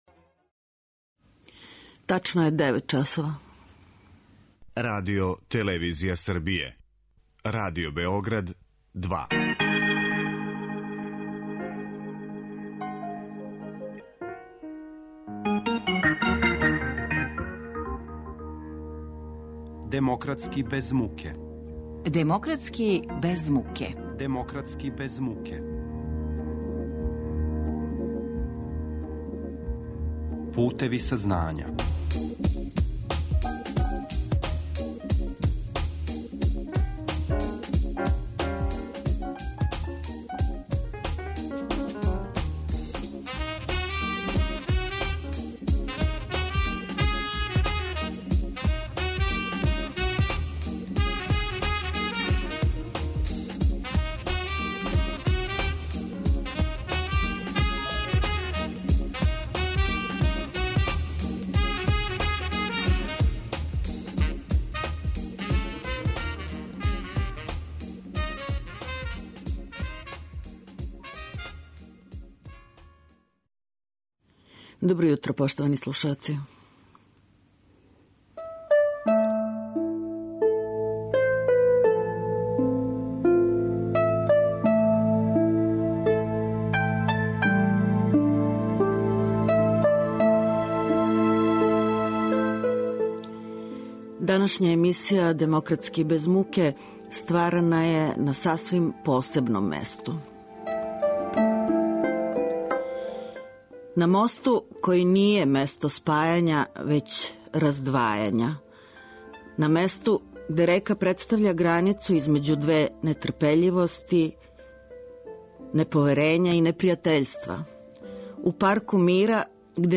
Поново актуелна, и после више од годину и по дана од како је снимљена - репортажа о мосту који дели Северну и Јужну Косовску Митровицу.